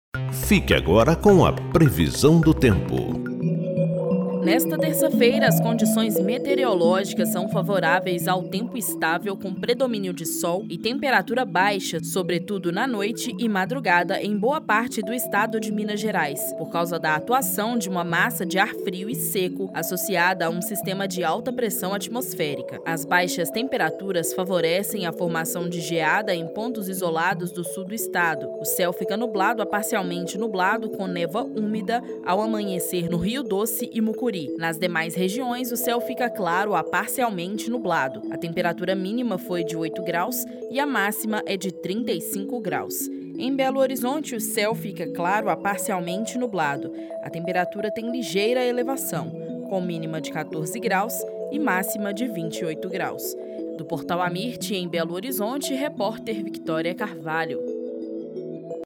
AMIRT Boletins Diários Destaque Notícias em áudio Previsão do TempoThe estimated reading time is 1 minute